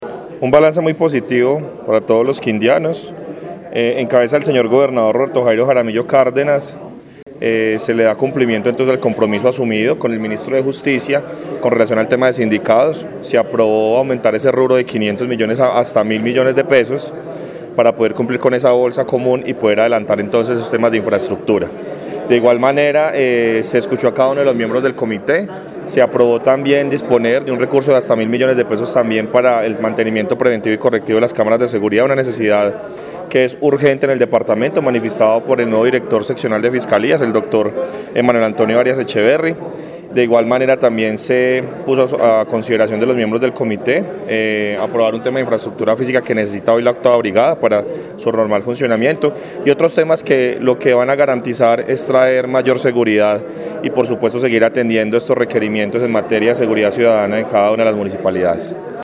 Audio: Jorge Hernán Zapata Botero, director de la Oficina Privada y gobernador encargado.